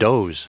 Prononciation du mot doze en anglais (fichier audio)
Prononciation du mot : doze